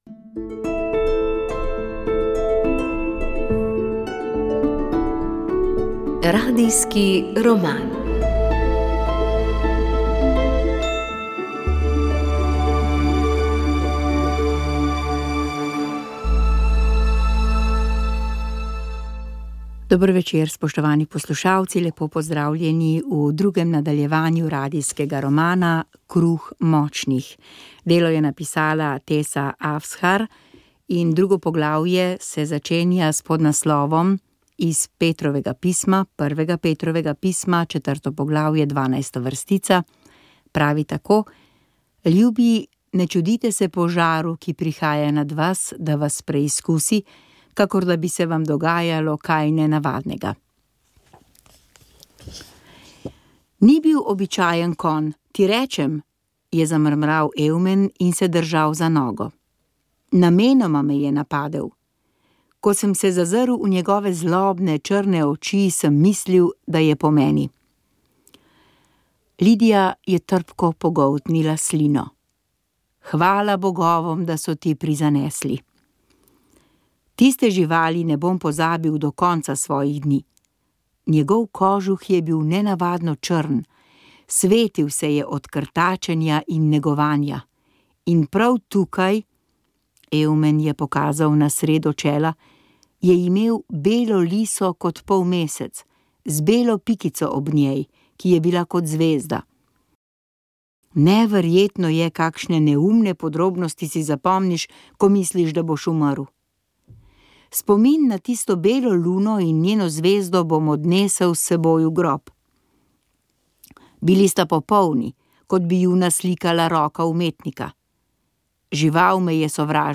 Radio Ognjišče knjiga Tessa Afshar Radijski roman VEČ ...